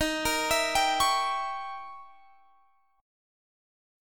Eb7b9 Chord
Listen to Eb7b9 strummed